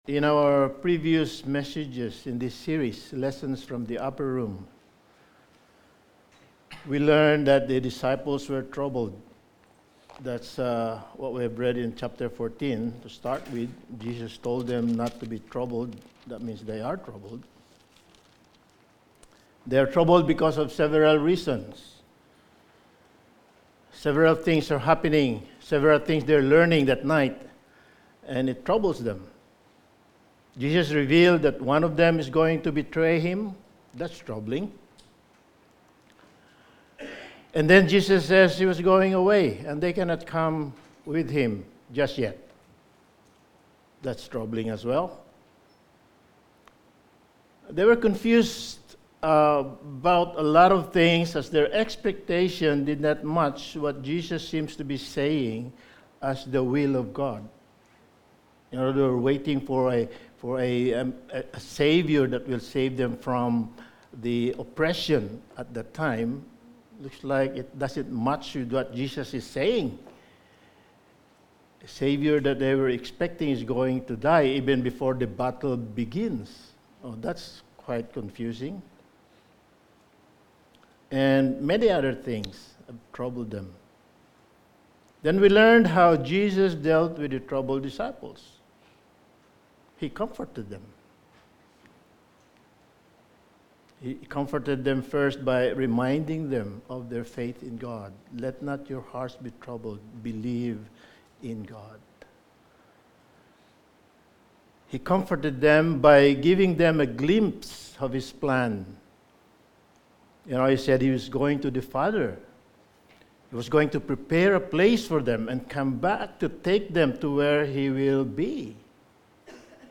Passage: John 14:15-31 Service Type: Sunday Morning